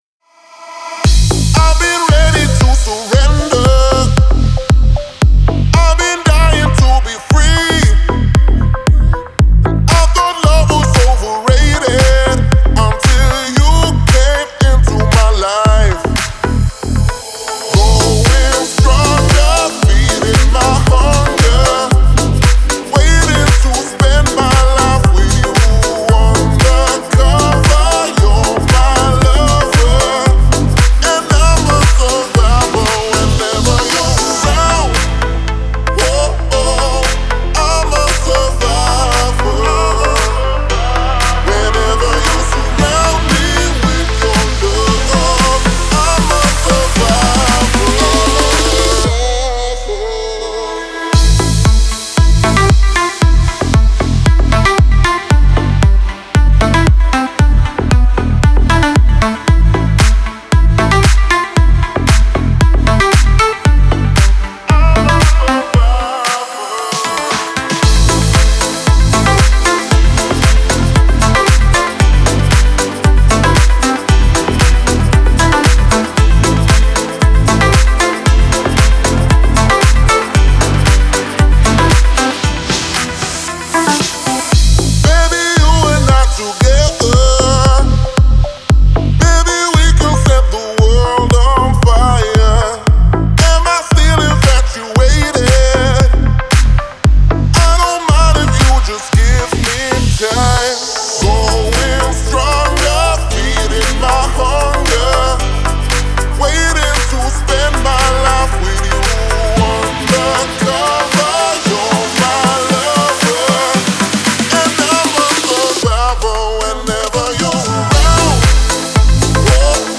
это энергичный трек в жанре рок